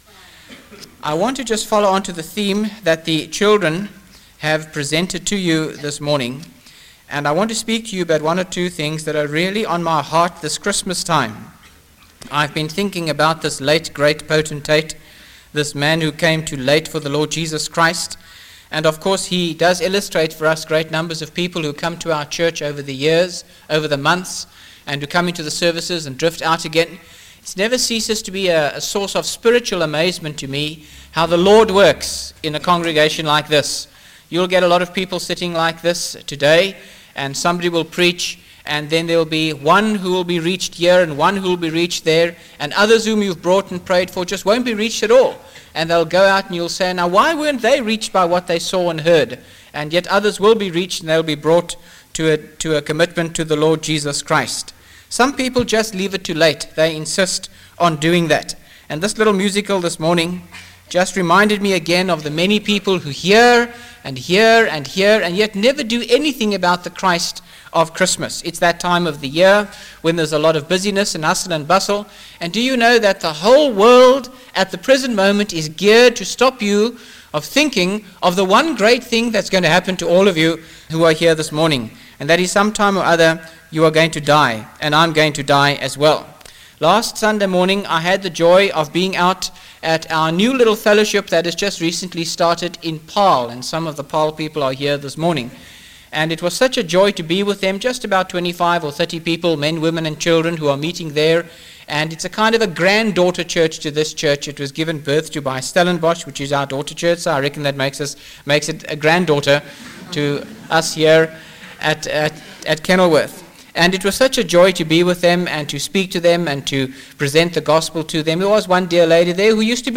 by Frank Retief | Jan 27, 2025 | Frank's Sermons (St James) | 0 comments